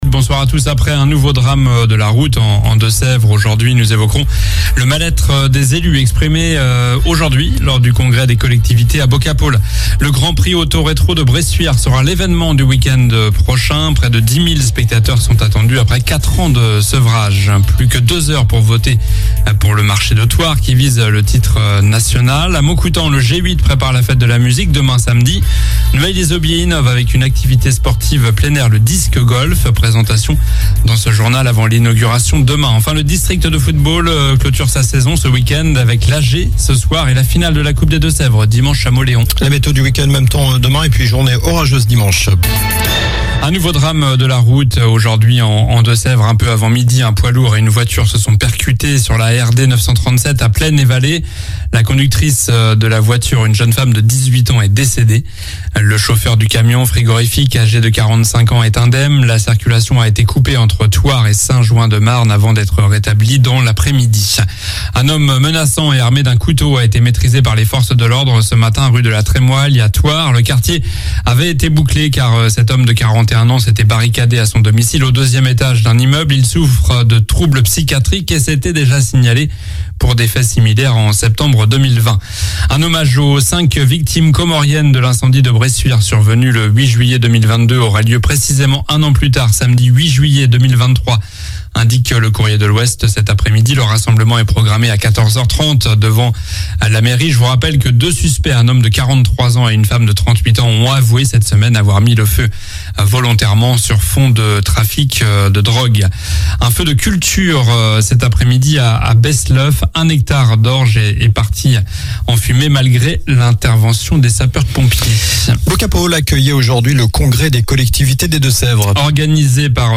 Journal du vendredi 16 juin (soir)